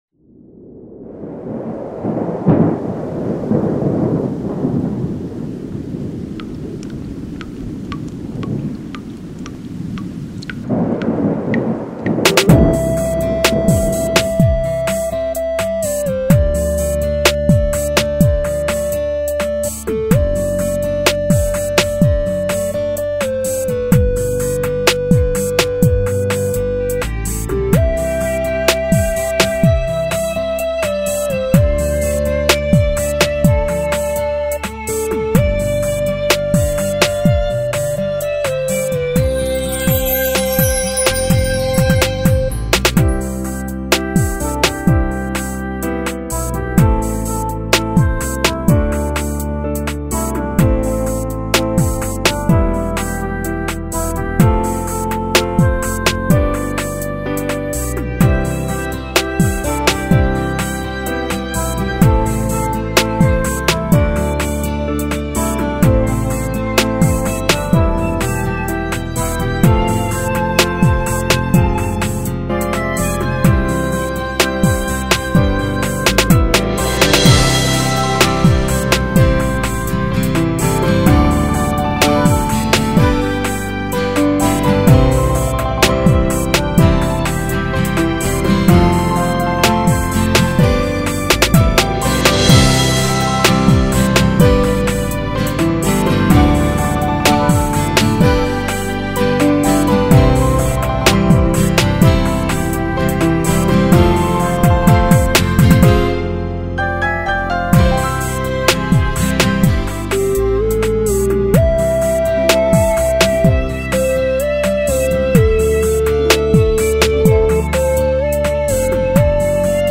音乐版：